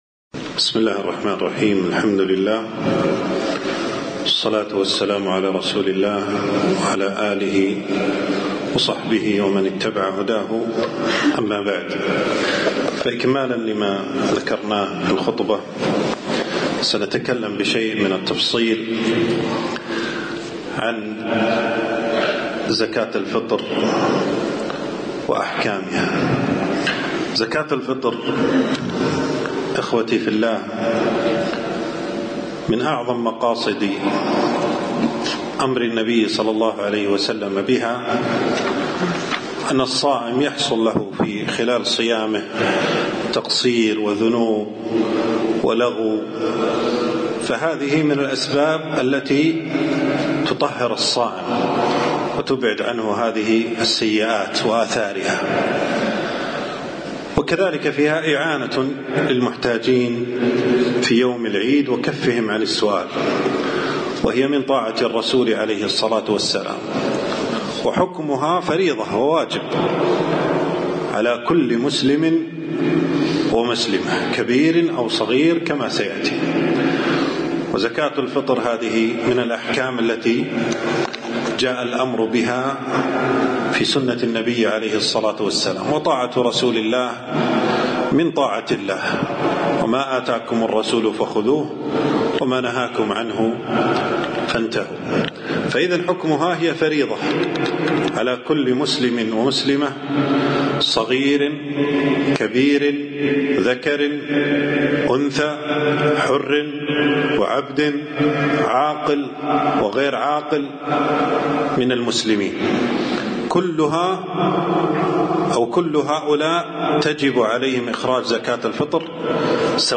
محاضرة - أحكام زكاة الفطر